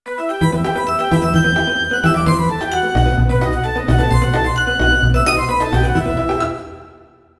Tonalidad de Mi menor. Ejemplo.
tristeza
triste
dramatismo
melodía
serio
severo
sintetizador
Sonidos: Música